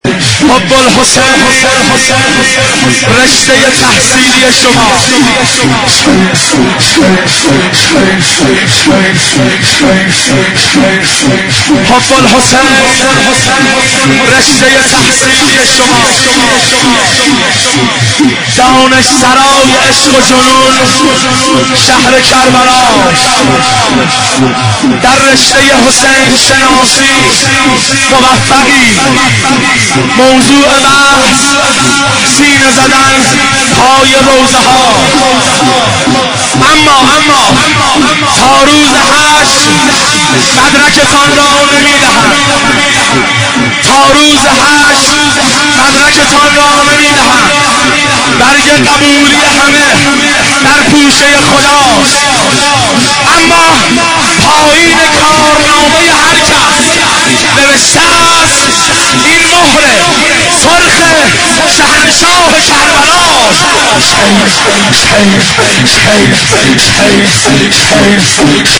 شعرخوانی شب سوم محرم الحرام 1389
شعرخوانی شب اول فاطمیه دوم 1390